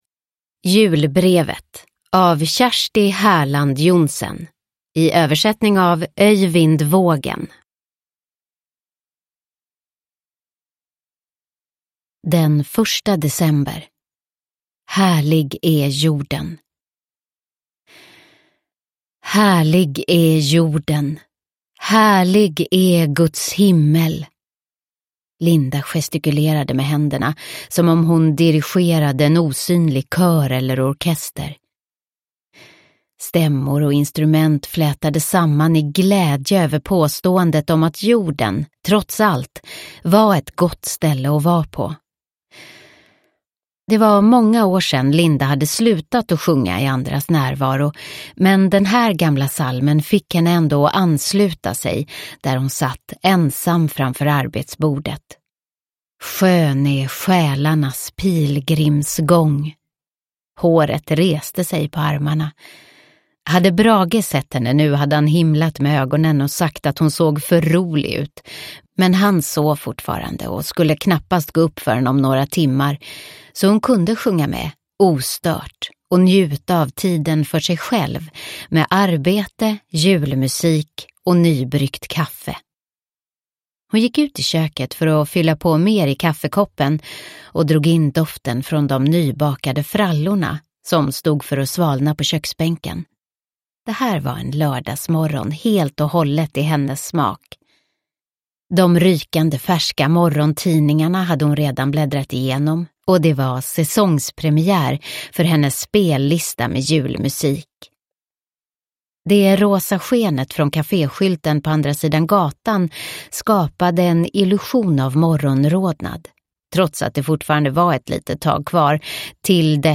Julbrevet – Ljudbok